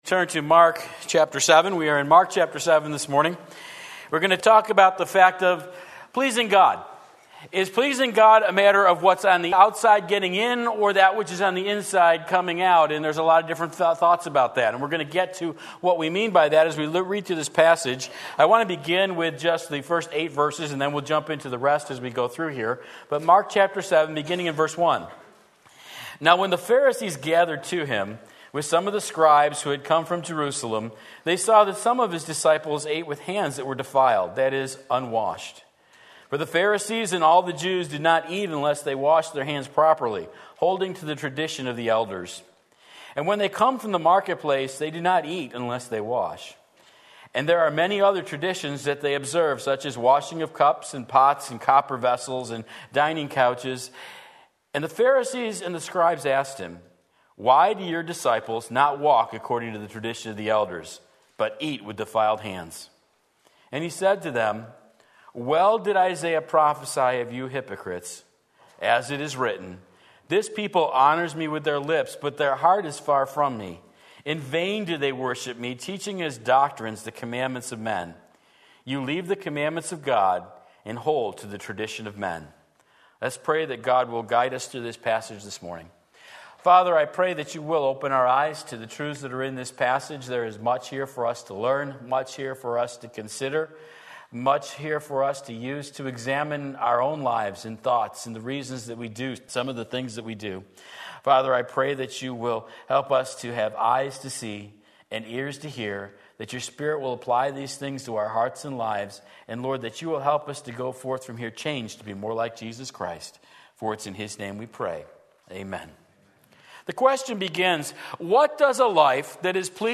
Sermon Link
Outside In or Inside Out Mark 7:1-23 Sunday Morning Service